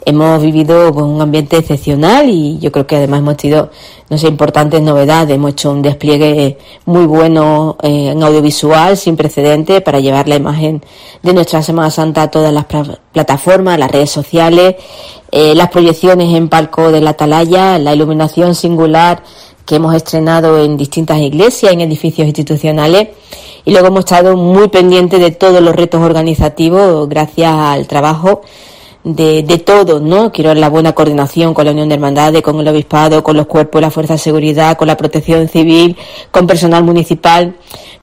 Escucha aquí a Mamen Sánchez, alcaldesa de Jerez